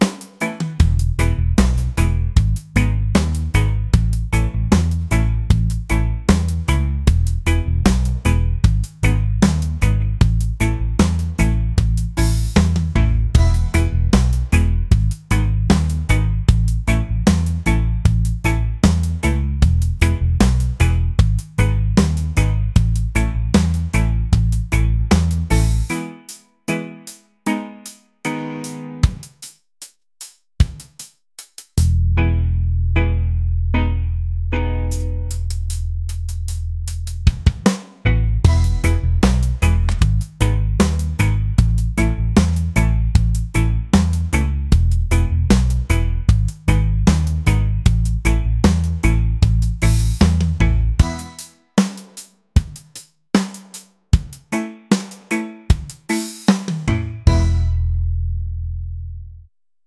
reggae | laid-back